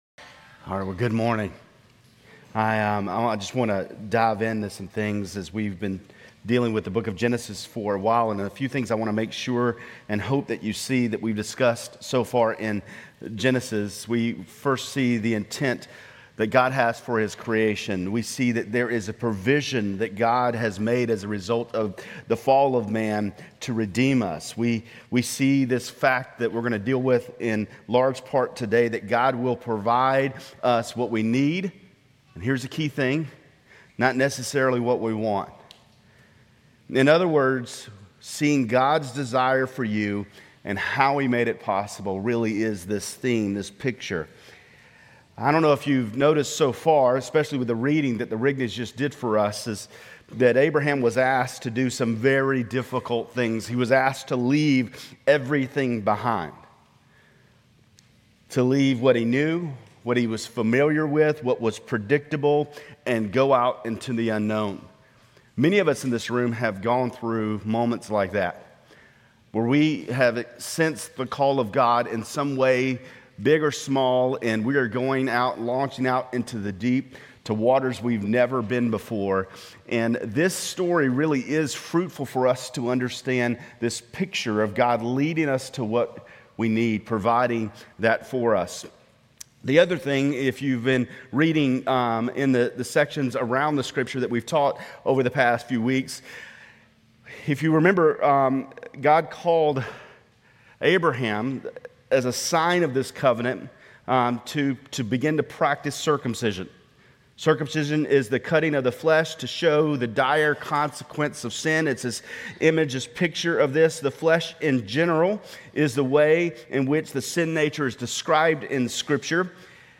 Grace Community Church Lindale Campus Sermons Gen 22 - Sacrifice of Isaac Oct 28 2024 | 00:25:24 1x 00:00 / 00:25:24 Subscribe Share RSS Feed Share Link Embed